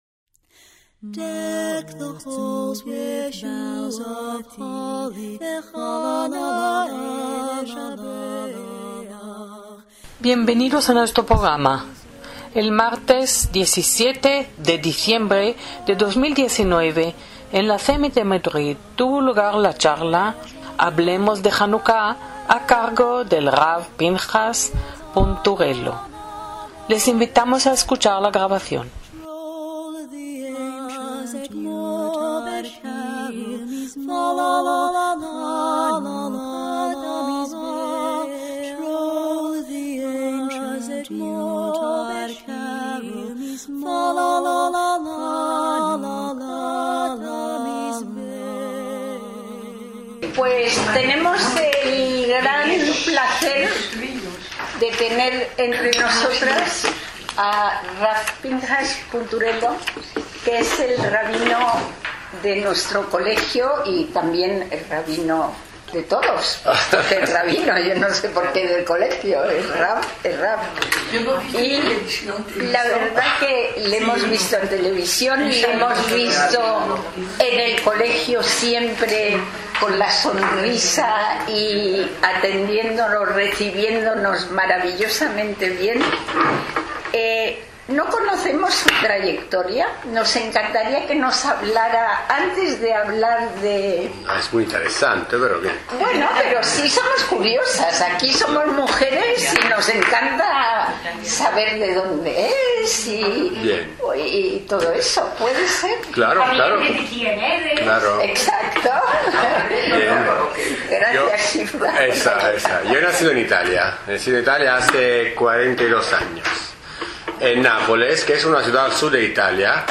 ACTOS EN DIRECTO - Janucá es la fiesta de las luces.